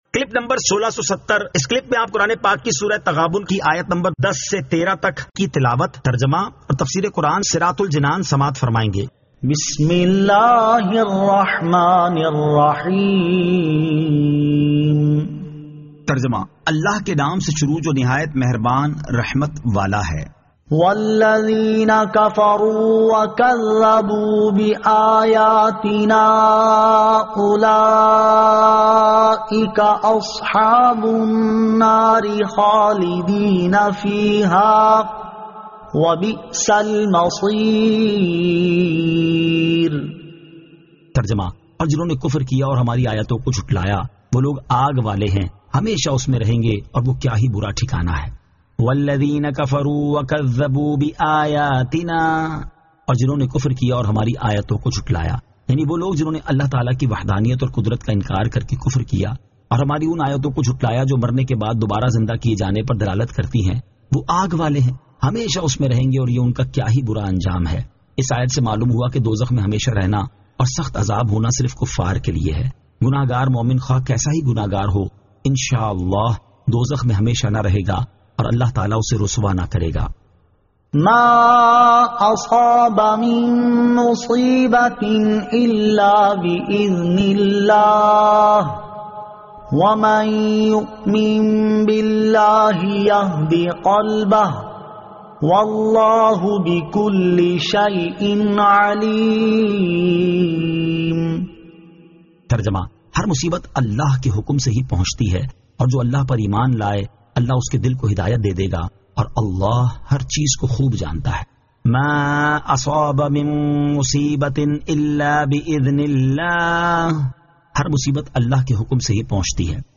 Surah At-Taghabun 10 To 13 Tilawat , Tarjama , Tafseer